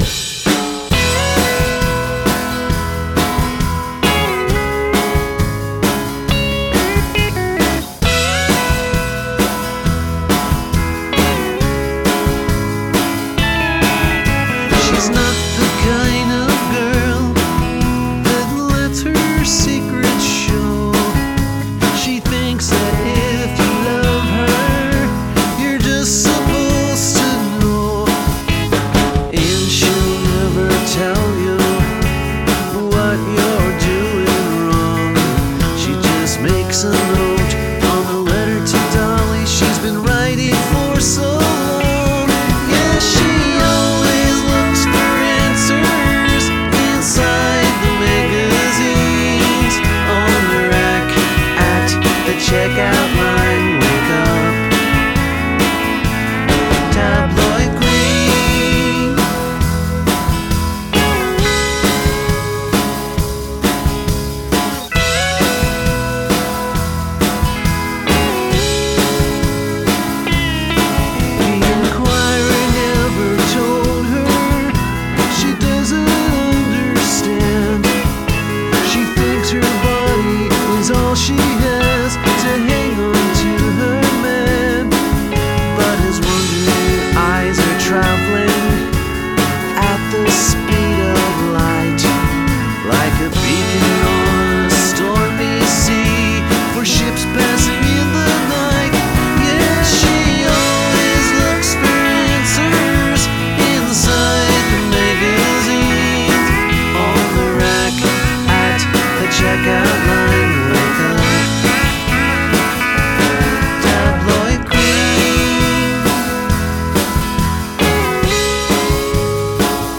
• Genre: Alt Country / Folk